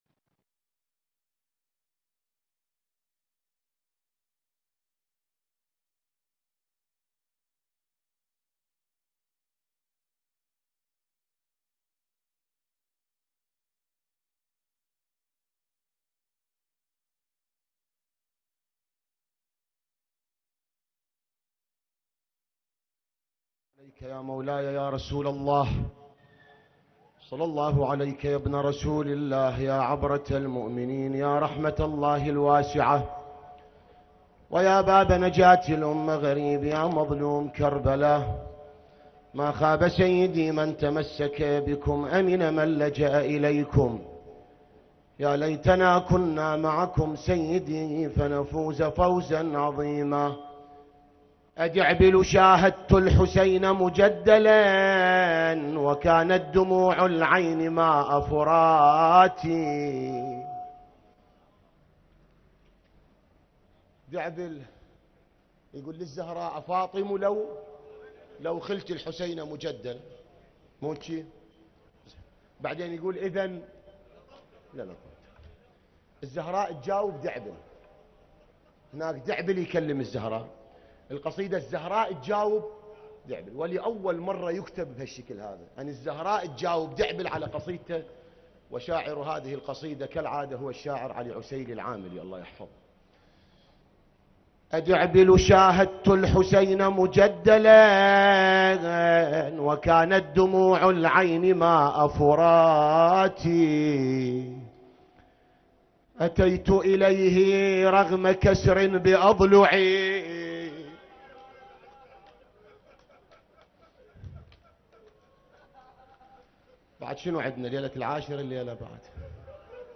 مصائب يوم العاشر و مصيبة عبدالله الرضيع ع نعي ليلة العاشر محرم الحرام 1443 هـ